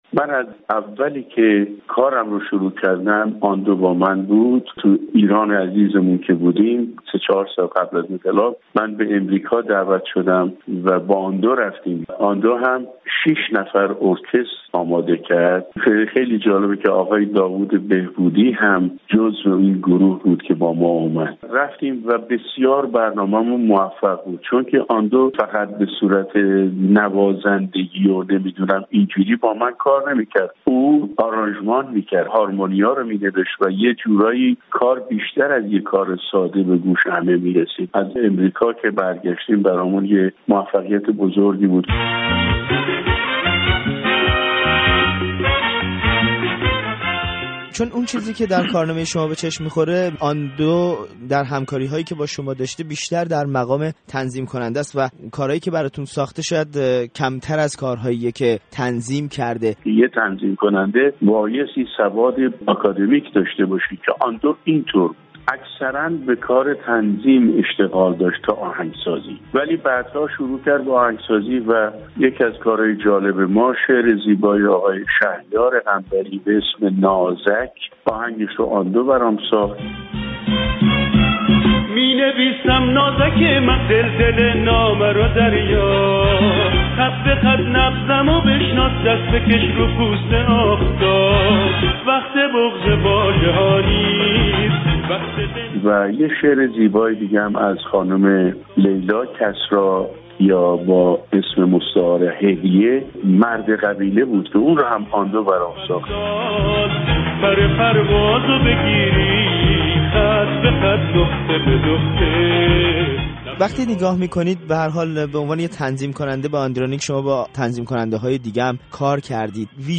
عارف خواننده موسیقی پاپ ایران با گریزی به سال های گذشته از همکاری‌اش با آندرانیک می‌گوید: